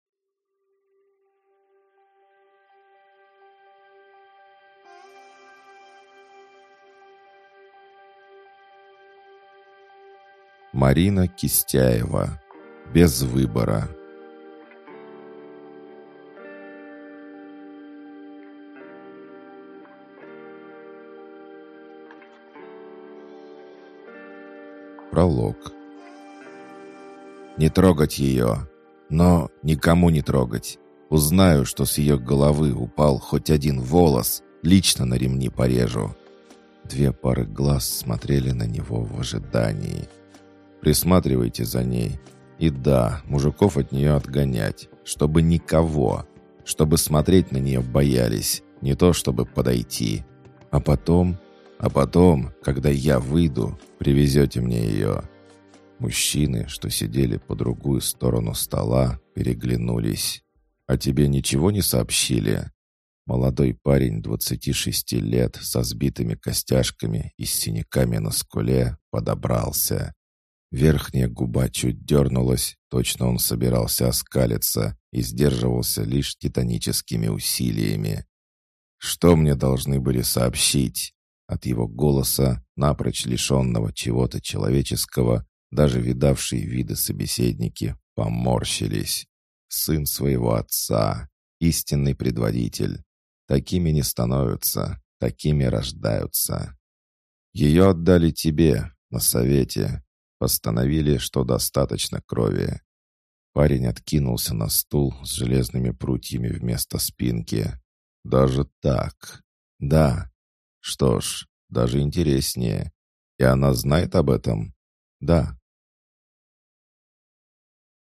Аудиокнига Без выбора | Библиотека аудиокниг